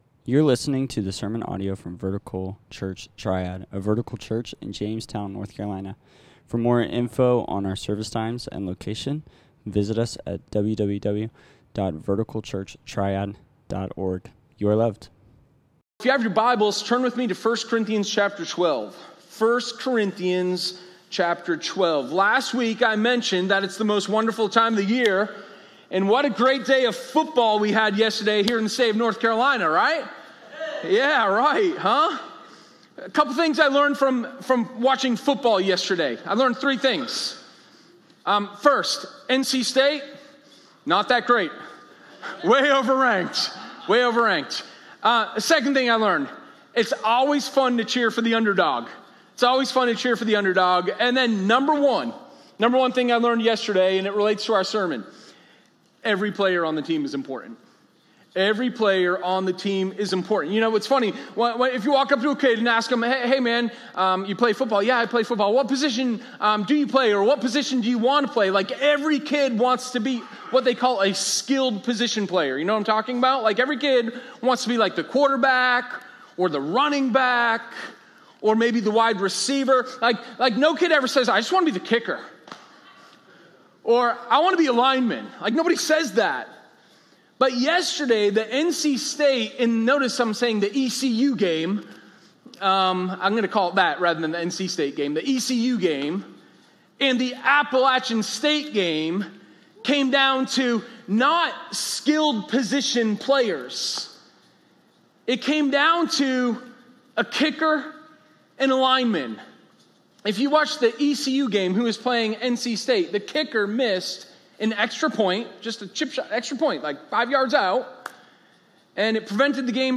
Sermon0904_Unity-in-Diversity.m4a